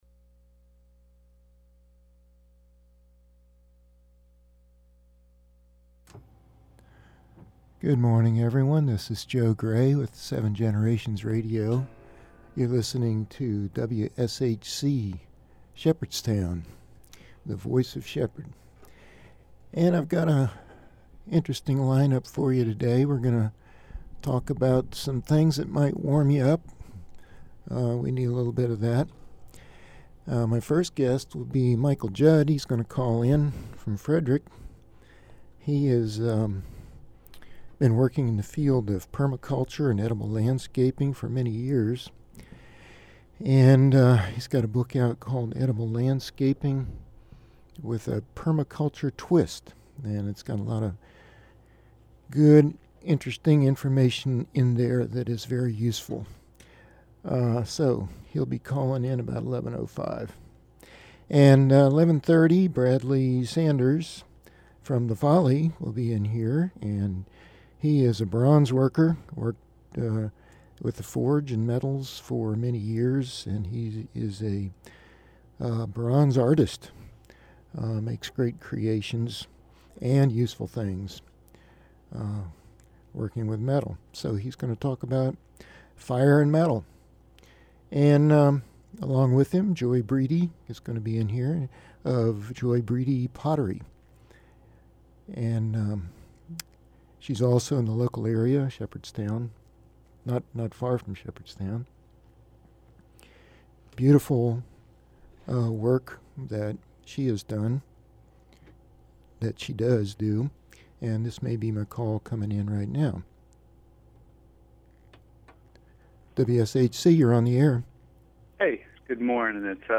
Broadcast on WSHC radio from the campus of Shepherd University, Shepherdstown, WV, March 7, 2015